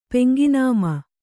♪ peŋgi nāma